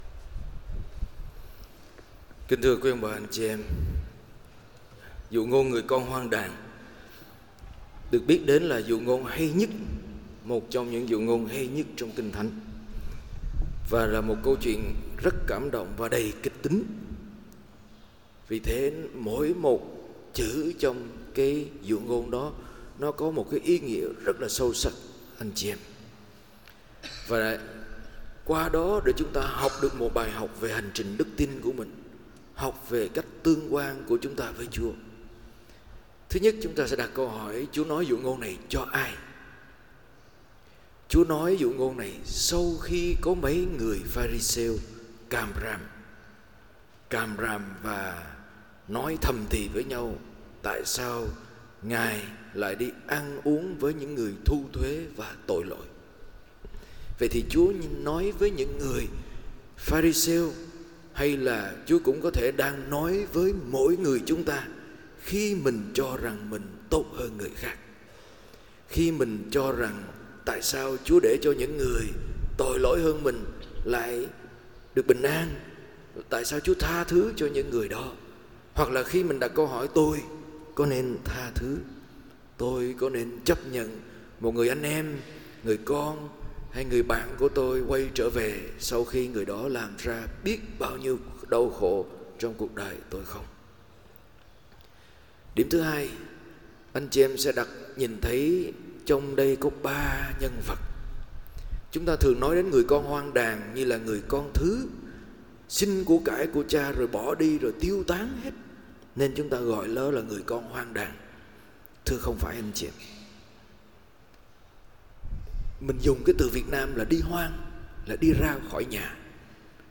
BaiGiang_CN_IV_MuaChay_C.mp3